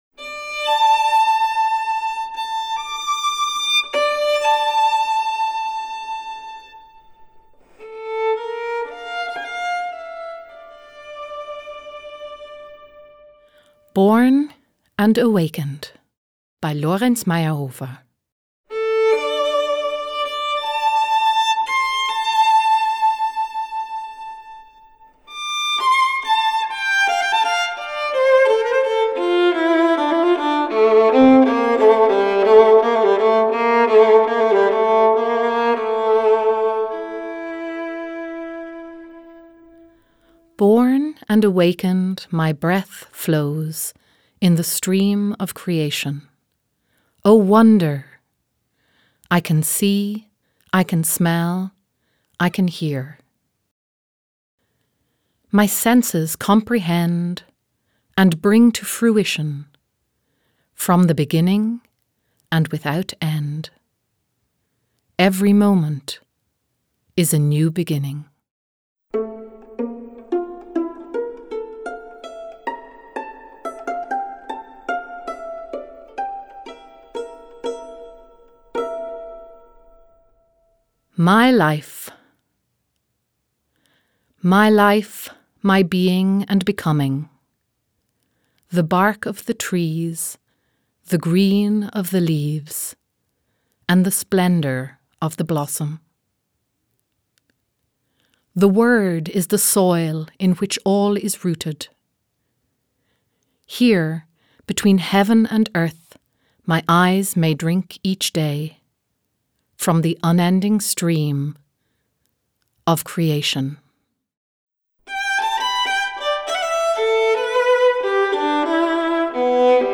BORN AND AWAKENED - 7 spiritual poems - Violine + Speaker
Born+and+awakened+++Violine.mp3